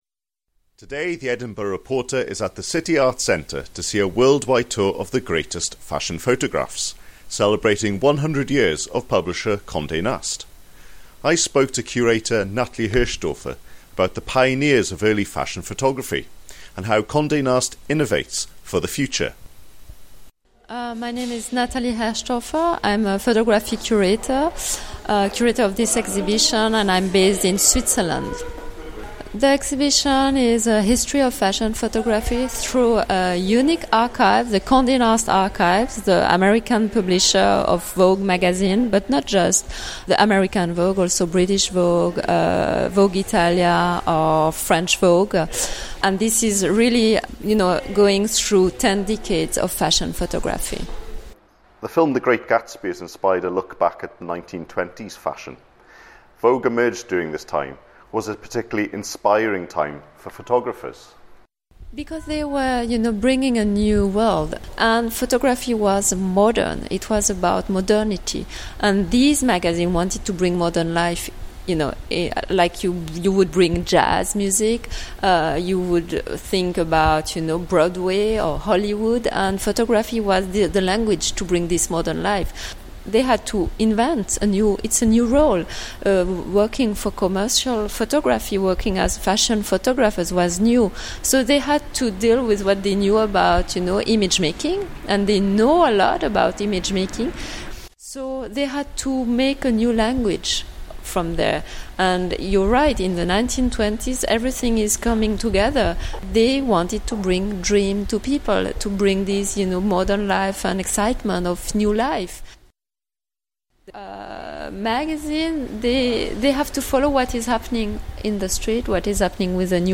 A celebration of 100 years of photography by the publishers of Vogue. Interview